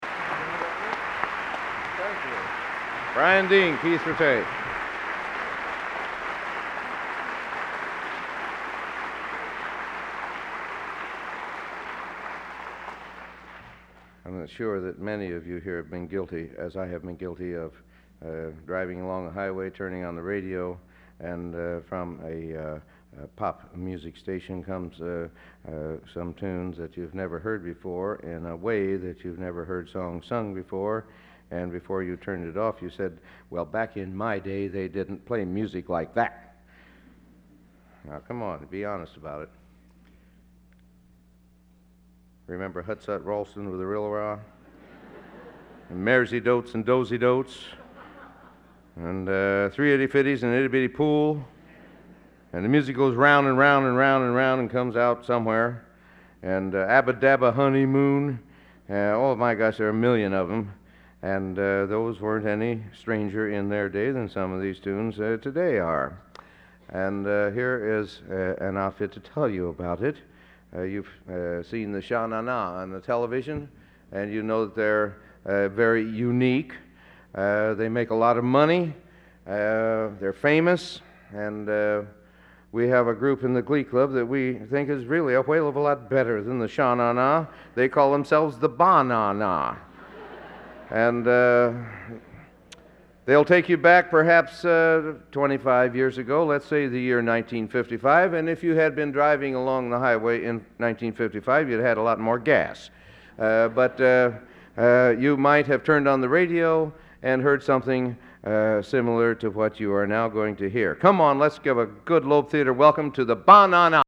Location: West Lafayette, Indiana
Genre: | Type: End of Season